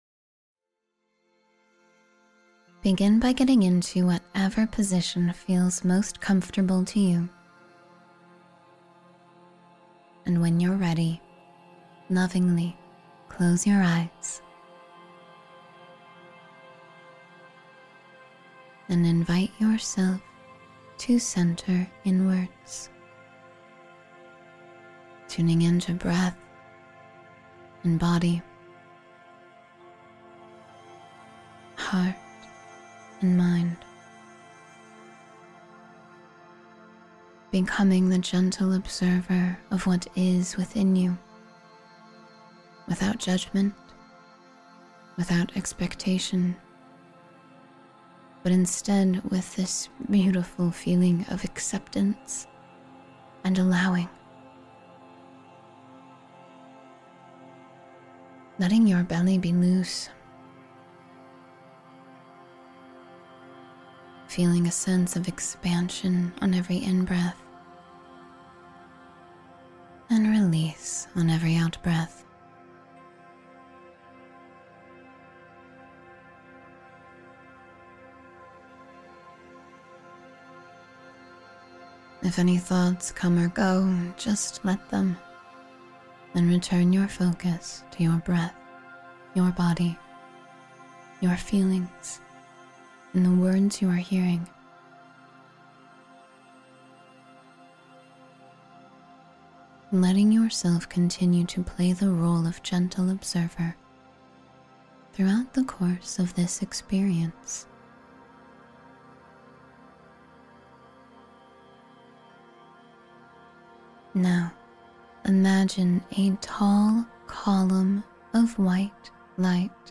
Manifest How You Want to Feel Today — 10-Minute Guided Meditation for Emotional Clarity